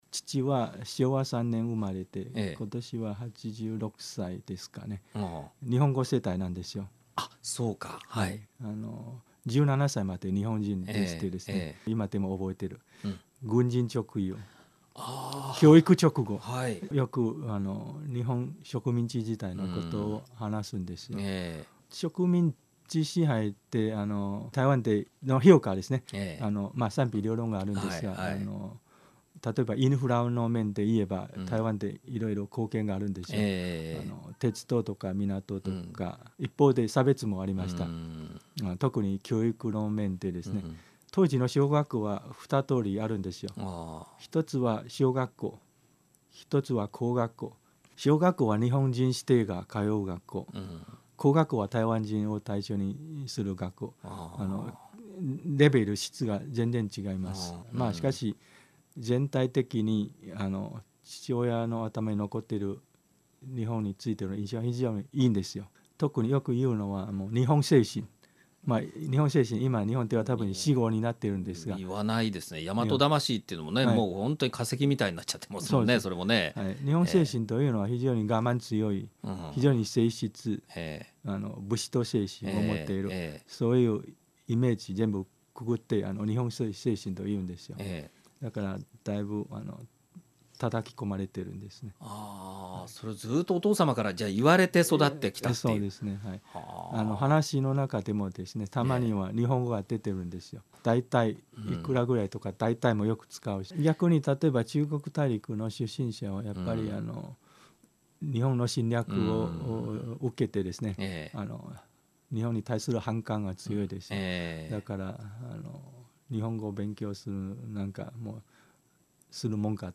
台北駐日経済文化代表処　那覇分処長　蘇啓誠さん